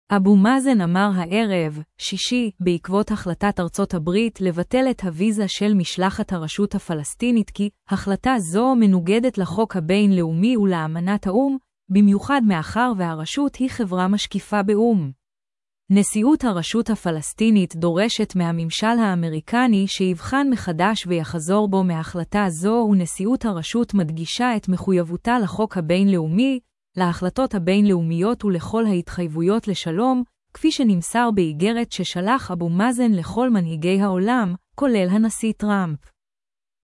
a-vu ma-a-zen a-mar ha-ʿe-rev (shi-shi) ba-ʿa-q-vo-t ha-kh-la-tat ar-ṣot ha-b-ri-t li-v-ṭel et ha-vi-za shel mis-l-khat ha-r-shut ha-f-las-ti-nit ki ha-kh-la-ta zo m-nu-g-det la-khok ha-b-in-l-u-mi v-la-e-ma-nat ha-u-m ba-m-yu-khad m-a-kher v-ha-r-shut hi-a kha-ve-ra mis-k-fi-a ba-u-m n-shi-u-t ha-r-shut ha-f-las-ti-nit do-re-shet m-ha-m-sh-l ha-a-m-ri-ka-ni shi-v-khan m-kh-dash v-y-kh-zor bo